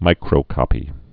(mīkrō-kŏpē)